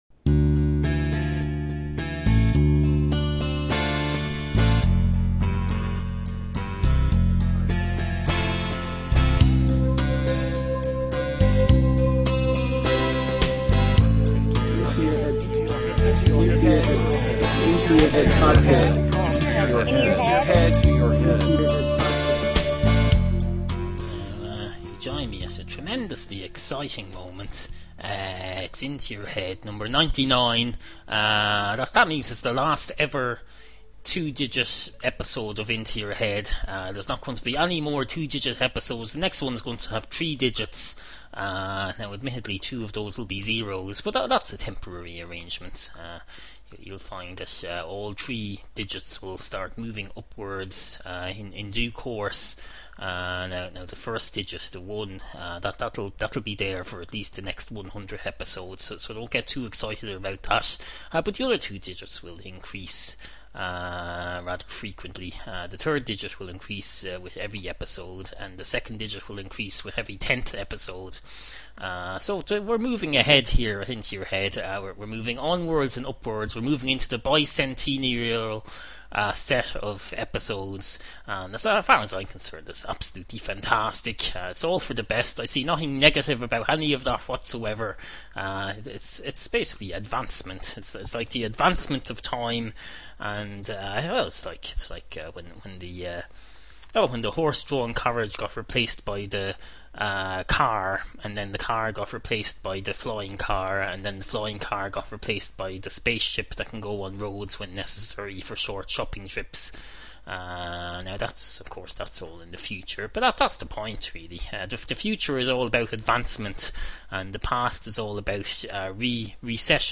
Tonight’s Topics Include: Flying cars and the three-digit era, Travelling against the flow of time, Travel sickness in the bedroom, Quad Etait Demonstrandum, Unsolicited extra education, The “Bozo The Clown” test, Declaring things null and void, Non-fatal ostrich eye injuries, The senior lifeguard’s big dilemna, Drawing lines in sand, A maths-based ball game in a bandstand. Also: Two brand new songs, specially produced to comply with trade descriptions regulatory requrements explained herein.